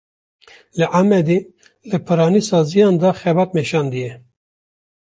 Pronúnciase como (IPA)
/xɛˈbɑːt/